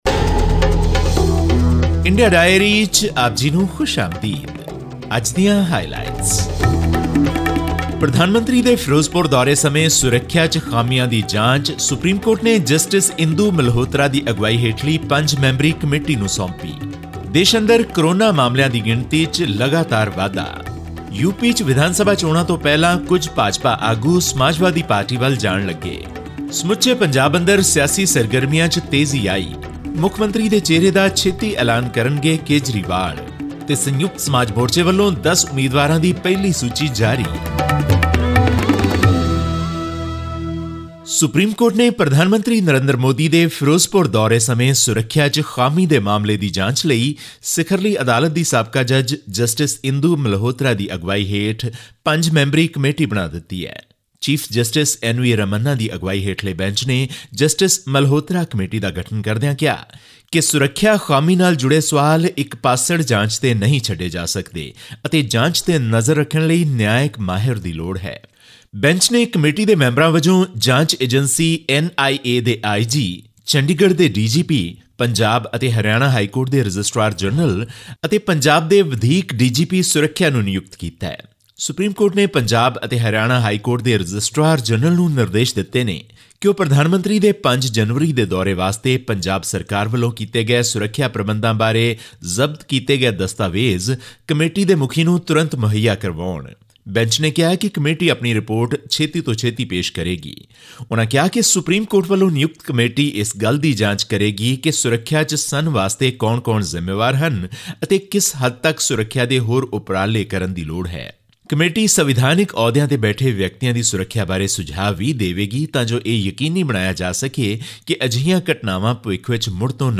The Supreme Court-appointed a five-member committee headed by justice Indu Malhotra to probe the “major breach” in Prime Minister Narendra Modi’s security while he was on his way to address an election rally in Punjab’s Ferozepur city. All this and more in our weekly news segment from India.
Click on the player above to listen to this news bulletin in Punjabi.